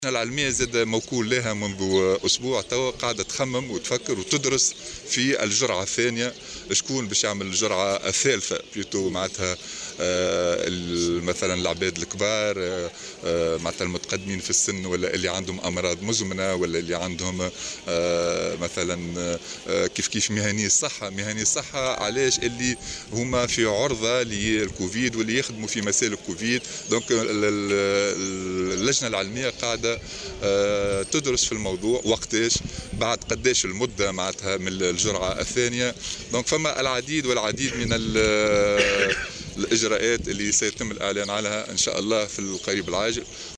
وأضاف في تصريح لمراسل "الجوهرة أف أم" على هامش زيارته اليوم لولاية القصرين، أن الأولوية ستكون لكبار السن وأصحاب الأمراض المزمنة وكذلك مهنيي الصحة الذين يعملون في مسالك الكوفيد.